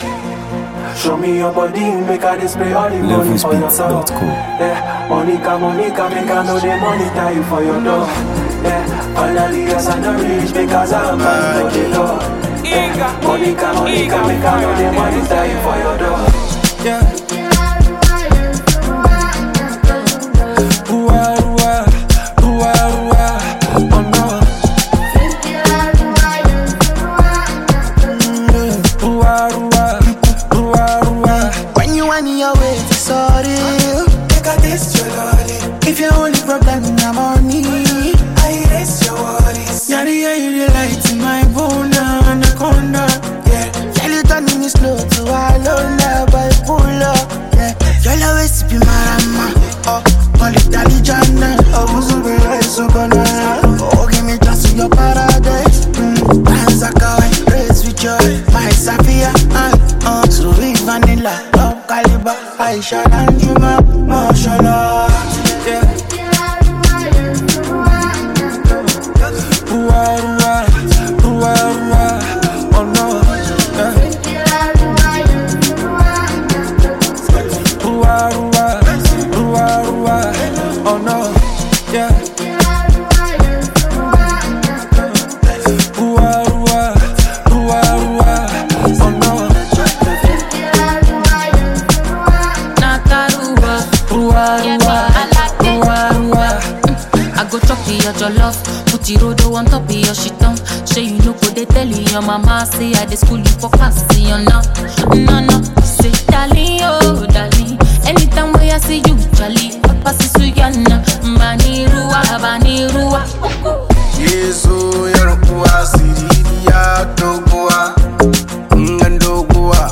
Ghana Music 2025 3:03
Known for his soulful delivery and dynamic artistry
a sound that blends emotion, rhythm, and originality